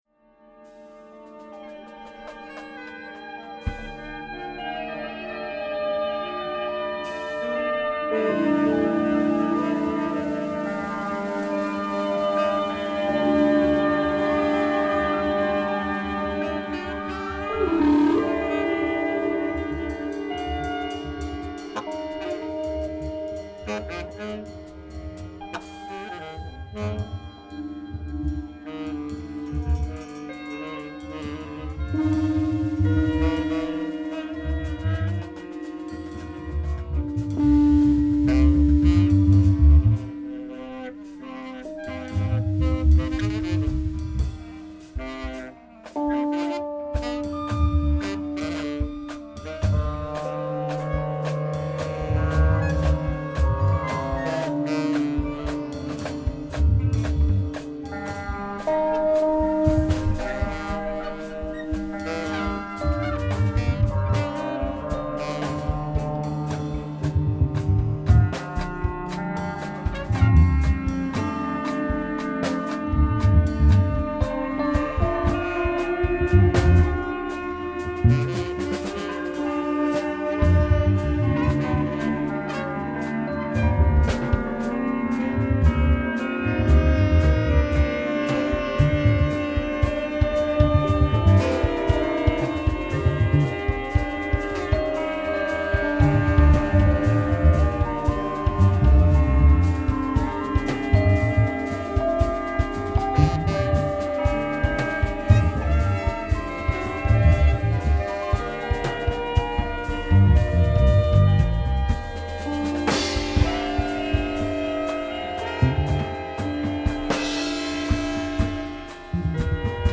free jazz band